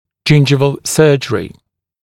[‘ʤɪnʤɪvəl ‘sɜːʤ(ə)rɪ] [ʤɪn’ʤaɪvəl][‘джиндживэл ‘сё:дж(э)ри] [джин’джайвэл]десневая хирургия, хирургическая коррекция десны, пластика десны